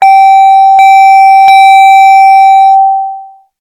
Play Warning Chime 2 - SoundBoardGuy
Play, download and share Warning chime 2 original sound button!!!!
warning-chime-2.mp3